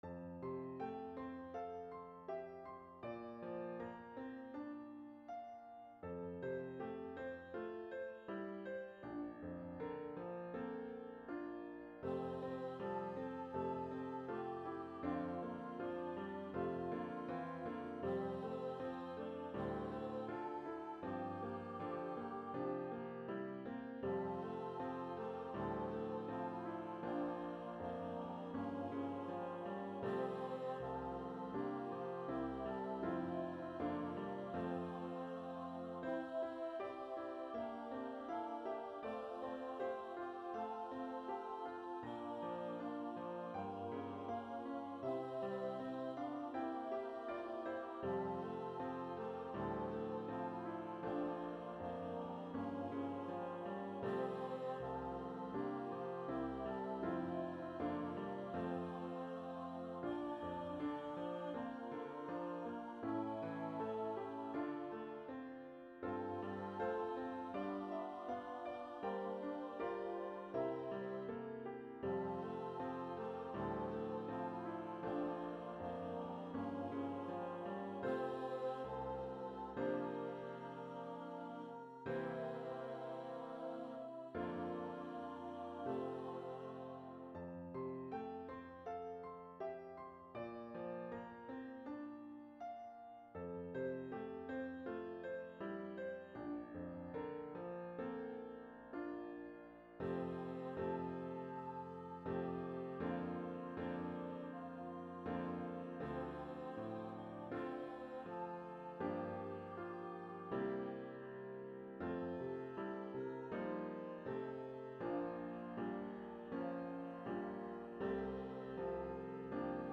SSA, Young Women Voices
This is my newest voicing of the hymn - in this case, for 3-pt. treble voices.